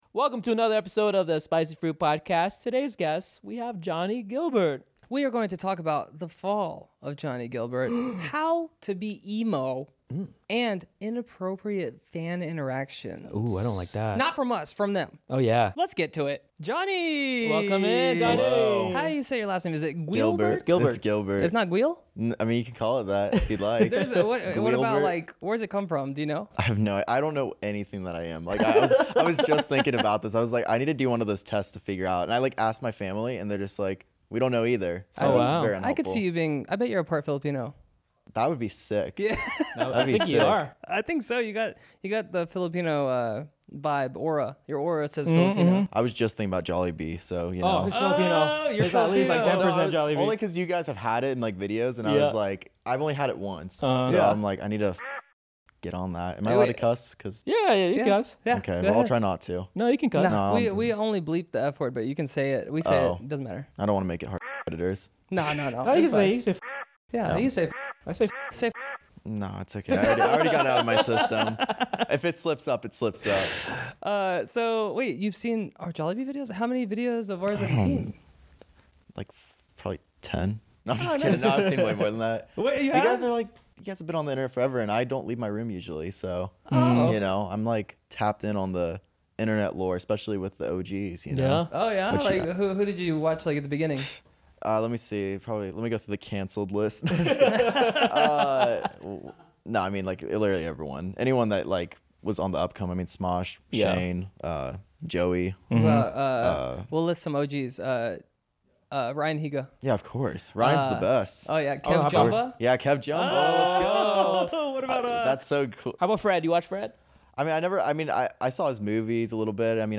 This was one of the most interesting interviews we've ever had on the Spicy Fruit Podcast.